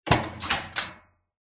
Открыли металлический отсек возле горелки